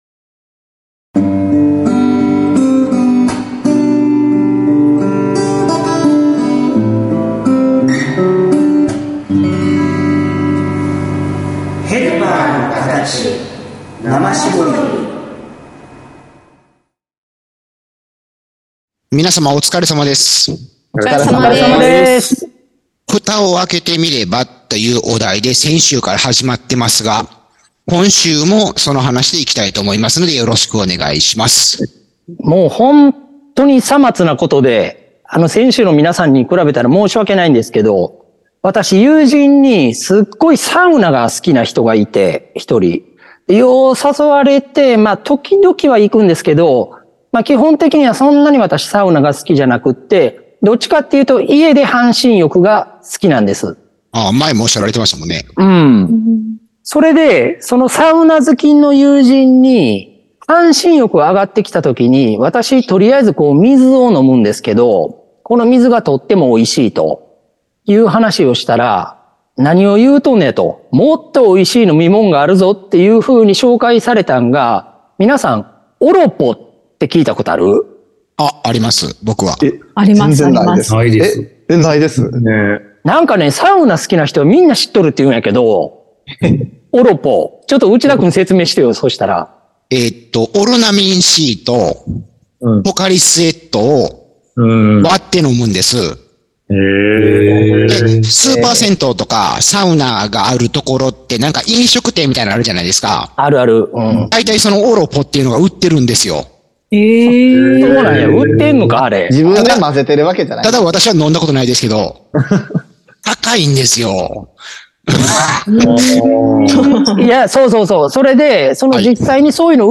＜今週のテーマ＞ 先週に引き続きまして、 「いざやってみたら、想像と 違った…」という趣旨の 談論を配信致します。